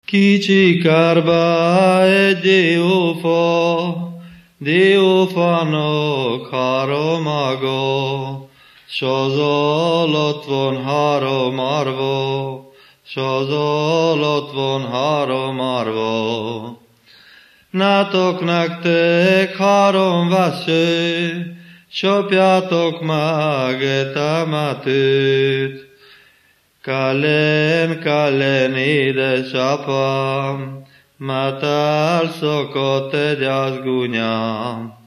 Moldva és Bukovina - Moldva - Trunk
ének
Műfaj: Ballada
Stílus: 3. Pszalmodizáló stílusú dallamok
Szótagszám: 8.8.8.8
Kadencia: 5 (b3) 1 1